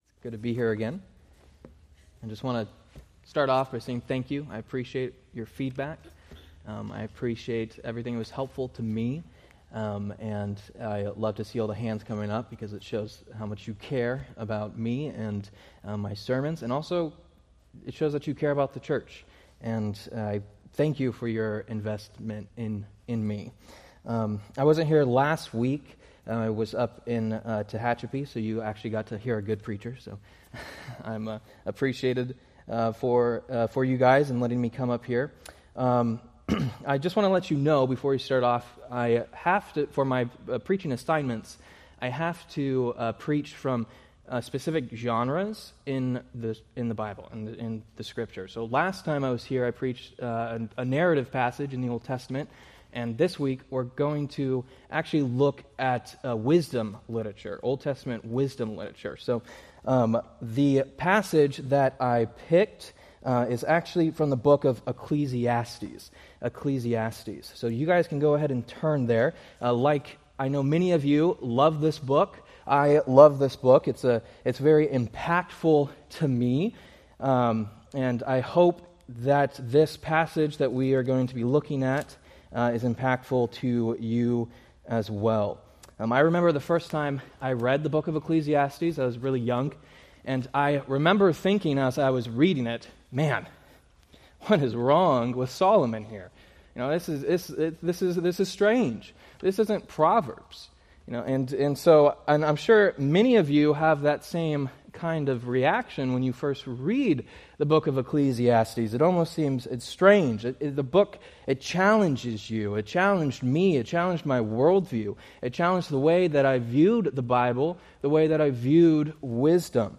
Date: Sep 7, 2025 Series: Various Sunday School Grouping: Sunday School (Adult) More: Download MP3 | YouTube